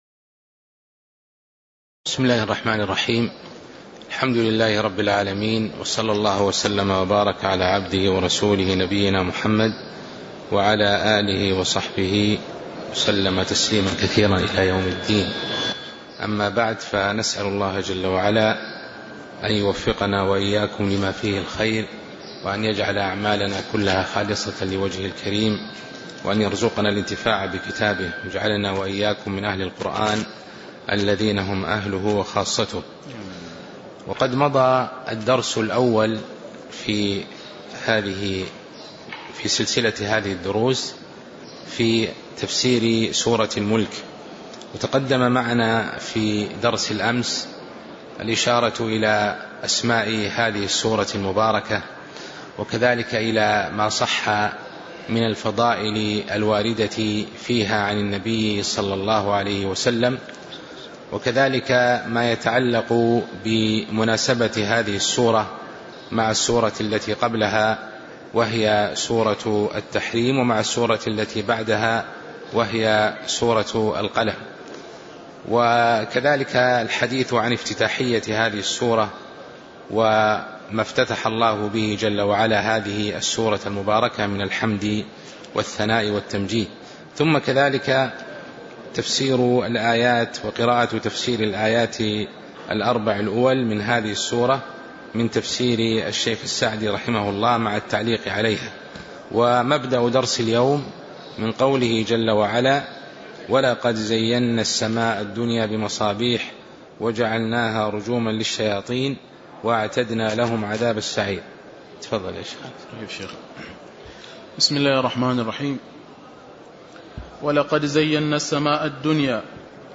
تاريخ النشر ١٠ ربيع الثاني ١٤٤٣ هـ المكان: المسجد النبوي الشيخ